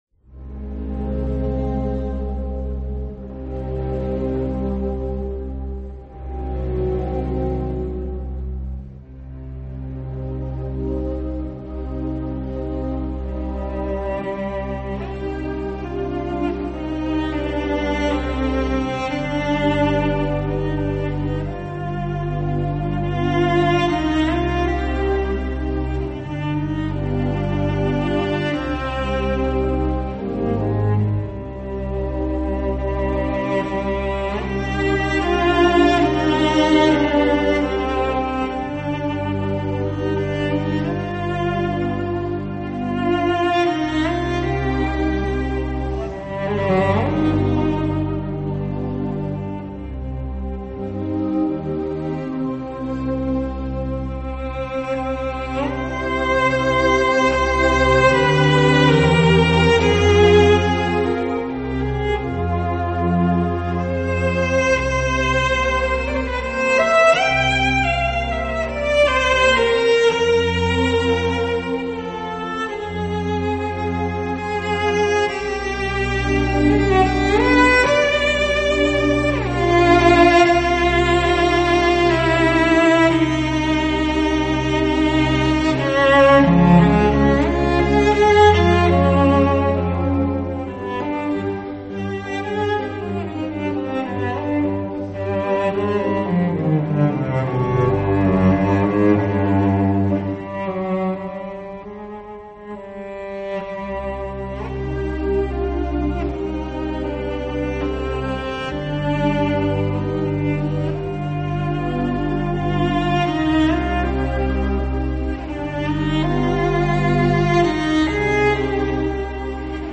大提琴曲 -[杰奎琳之泪]
似咏似叹的旋律，缓慢地击打心怀。沉淀已久的弦音，释放出无尽的哀思。眼泪在眶内满盈，沿着厚重深沉的如诉如泣的乐音，慢慢滑落，滑落。催人泪下的琴声中，黯色的乐魂翩然远逝，独舞于夜深人静的街头。流畅中带有哽噎的琴声，似乎在讲述一个久远的故事，让人窒息，浑身感到寒冷。